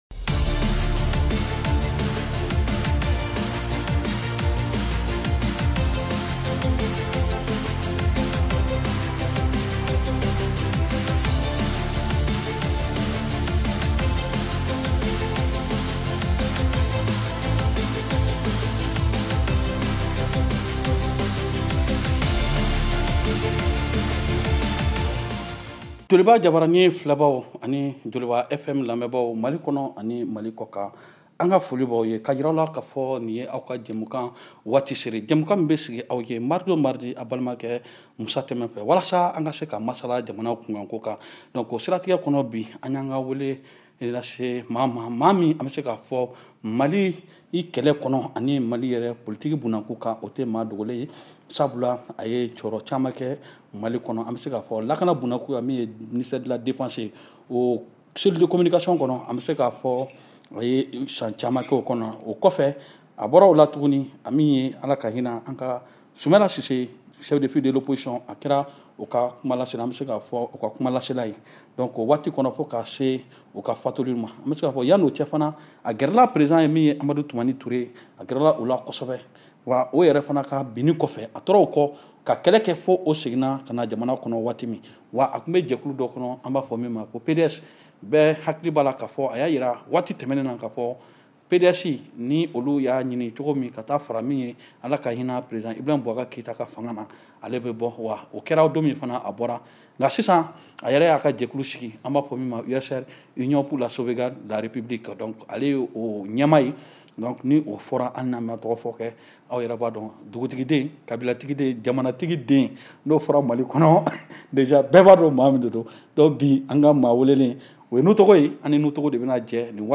Ècoutez votre émission de débat politique en bambara JÒ KÓNO.